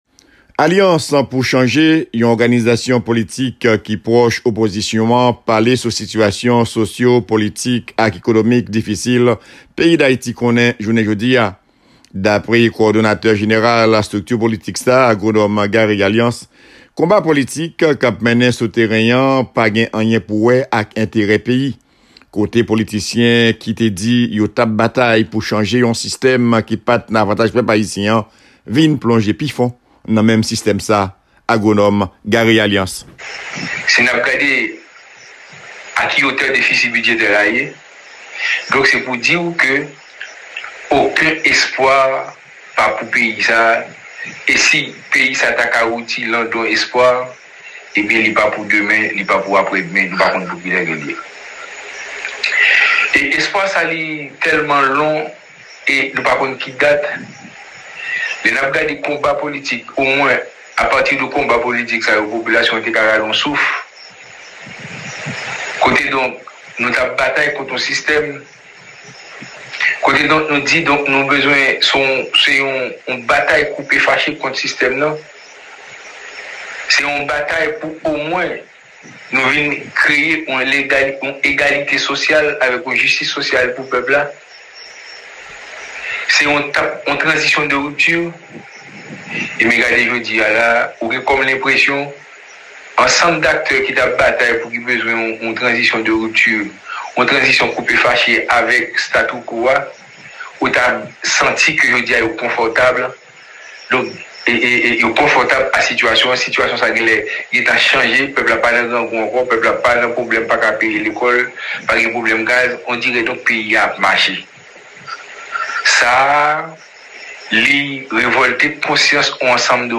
Alliance pou change yon oganizasyon politik ki pwoch opozisyon an drese yon bilan négatif sou situation peyi d'Ayiti plis ke 4 mwa apre asasina Prezidan Jovenel Moise. Yon reportage